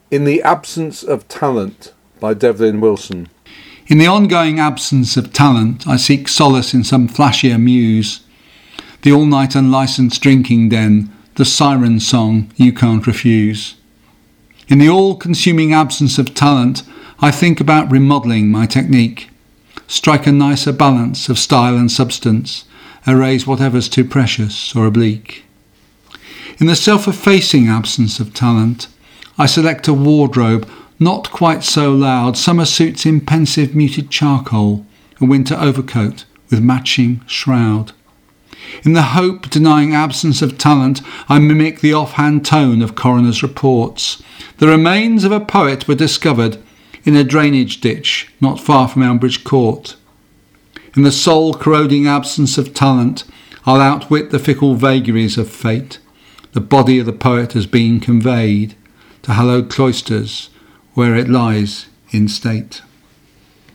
Here are some examples of me reading verse and pros by others:-